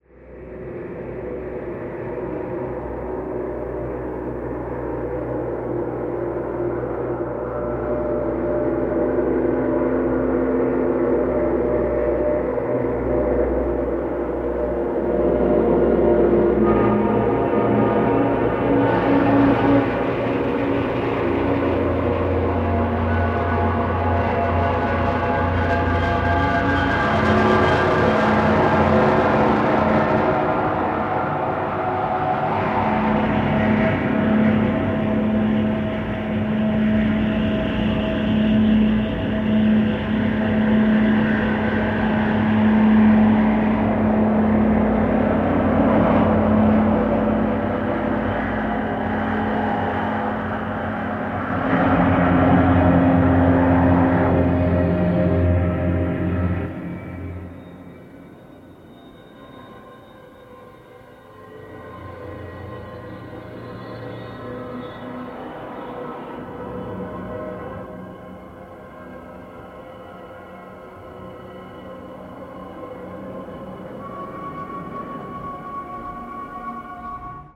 longform electroacoustic composition